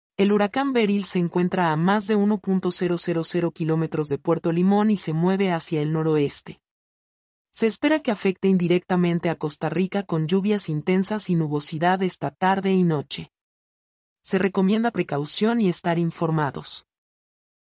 mp3-output-ttsfreedotcom-11-1.mp3